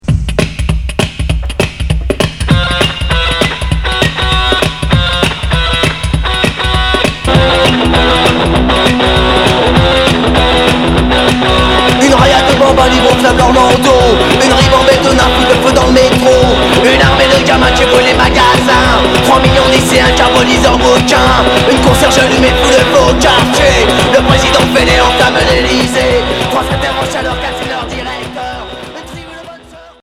Punk Alternatif Deuxième Maxi 45t